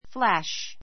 flash flǽʃ ふ ら シュ 動詞 ❶ ぱっと光る, ひらめく; ひらめかせる, ぱっと照らす The lightning flashed across the sky.